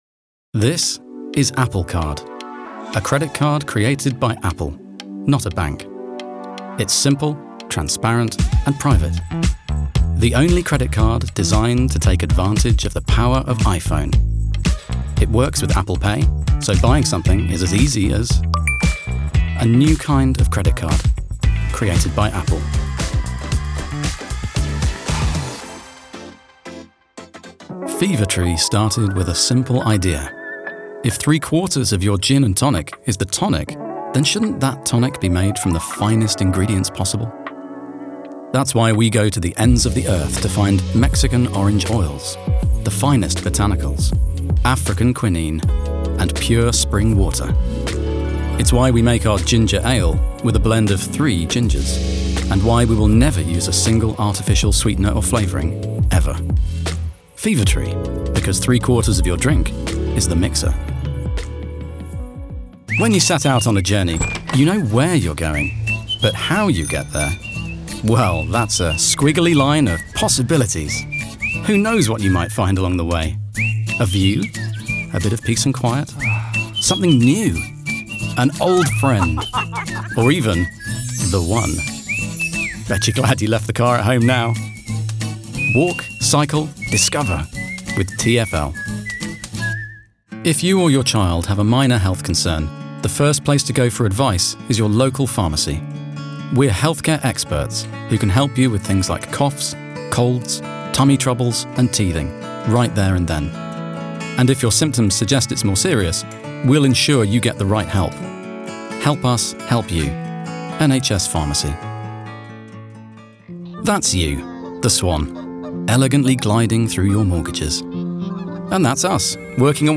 His voice is smooth, reassuring and professional.
Male / 30s / English / Southern
Showreel